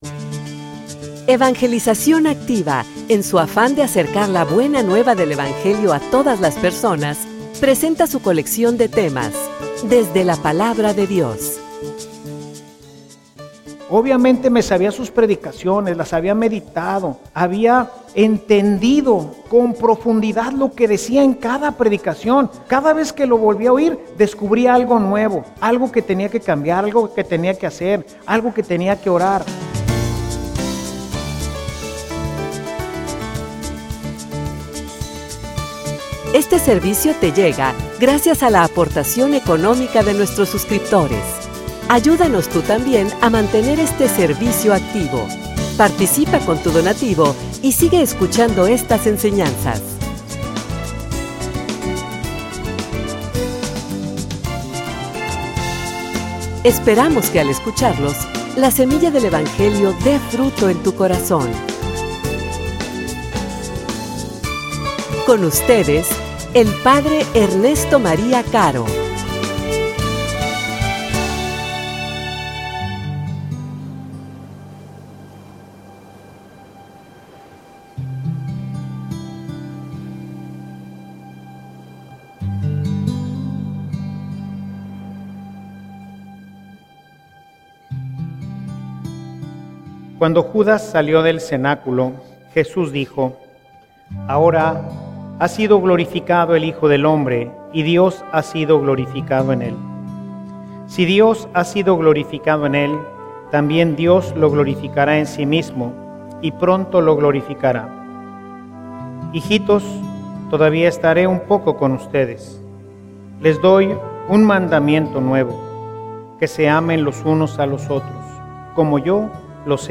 homilia_La_vida_nueva.mp3